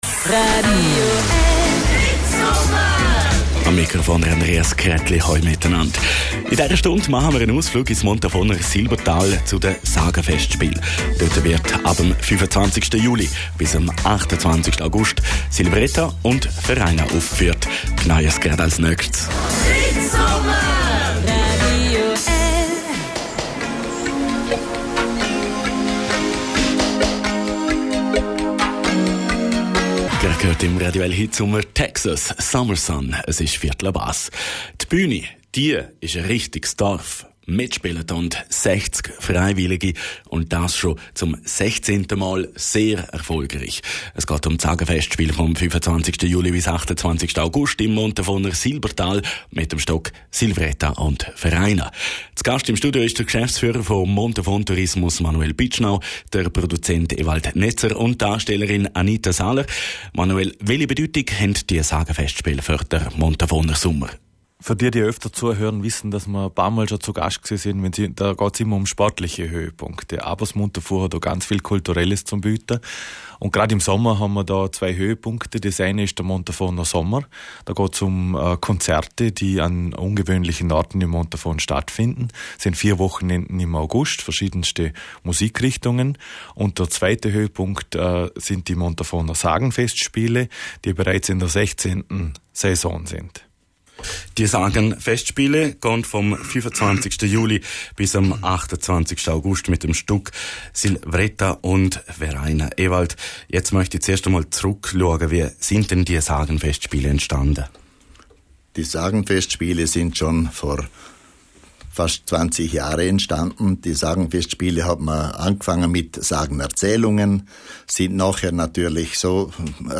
Liechtensteinischer Rundfunk (LRF) Sendungsmittschnitt